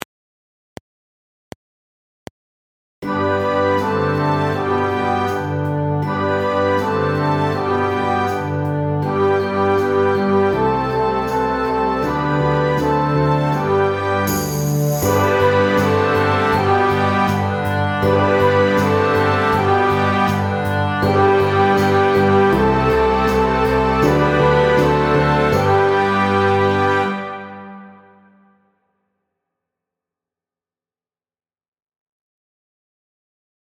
Exercise 4 – Rock Cross Buns Guide Track